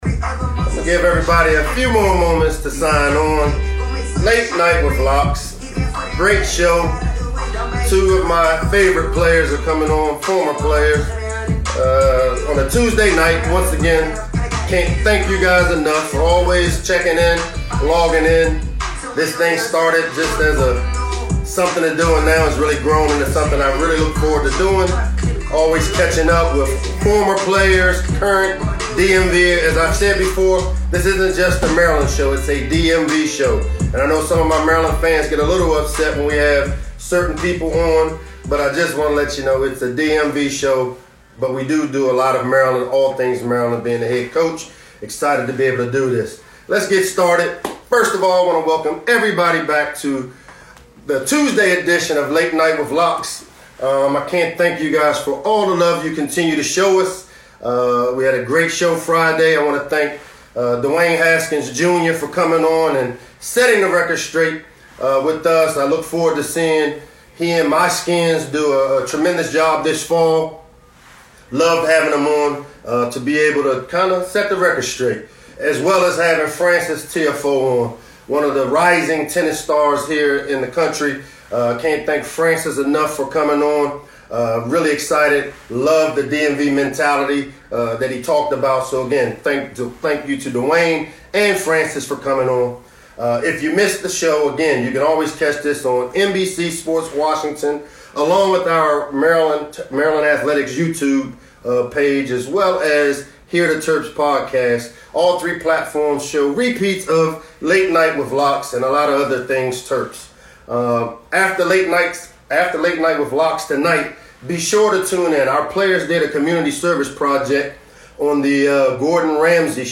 May 13, 2020 Late Night with Locks is an Instagram live show hosted by head football coach Michael Locksley every Tuesday and Friday evening at 7 p.m. This show featured nine-year NFL veteran Vontae Davis and former Maryland running back and Detroit Lion Ty Johnson .